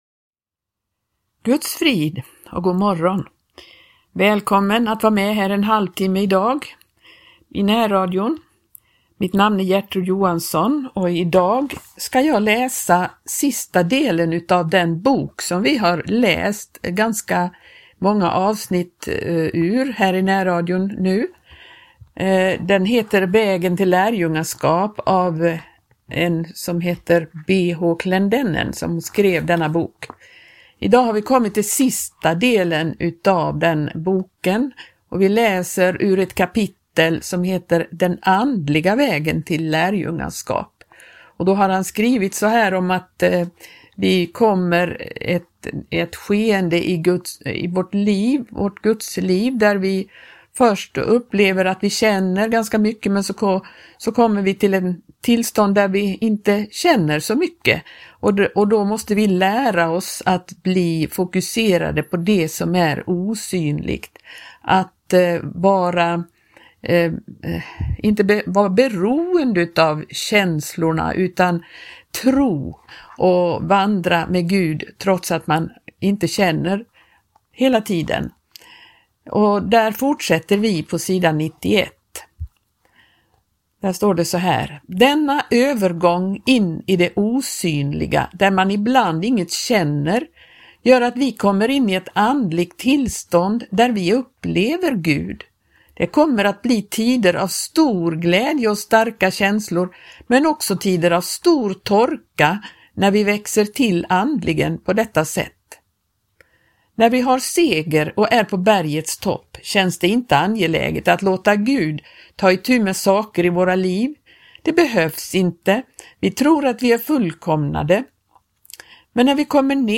Vägen till lärjungaskap (del 10) | Läsning